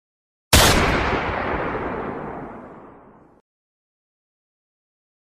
10 Shot Fired Botão de Som
Sound Effects Soundboard160 views